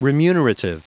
Prononciation du mot remunerative en anglais (fichier audio)
Prononciation du mot : remunerative